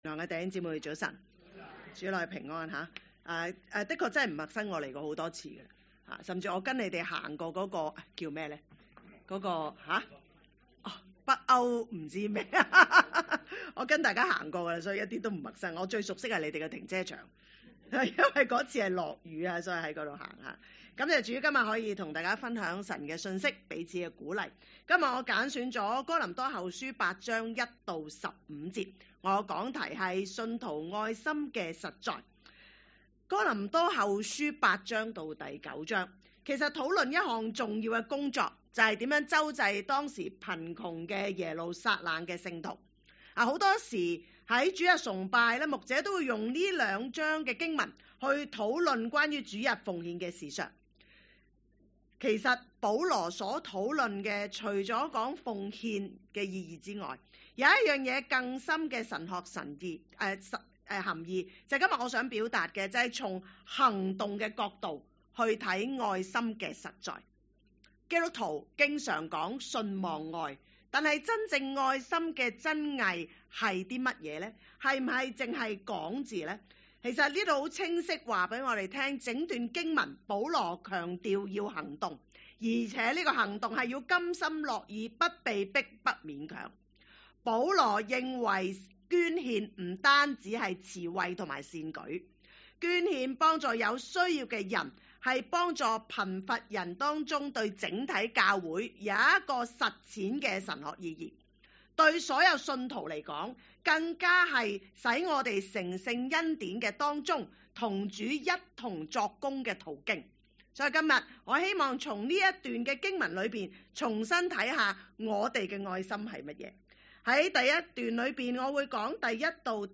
講道 ：信徒愛心的實在